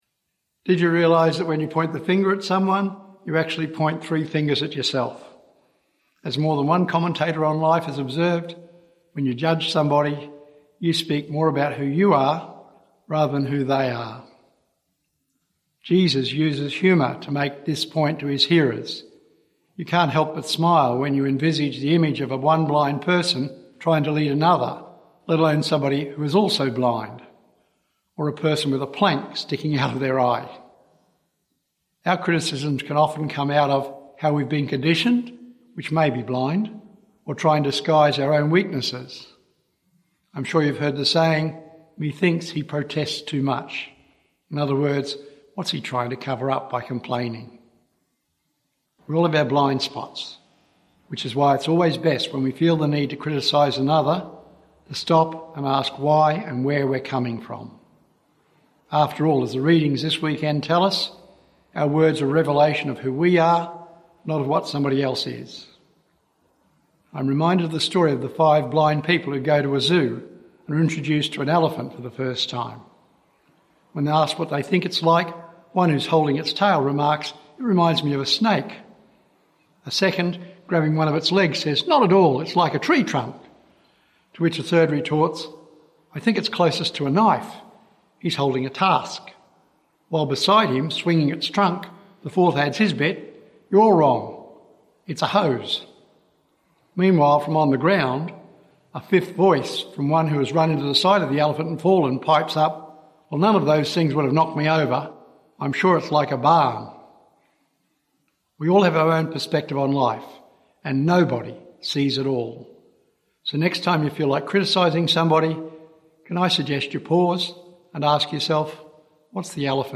Archdiocese of Brisbane Eighth Sunday in Ordinary Time - Two-Minute Homily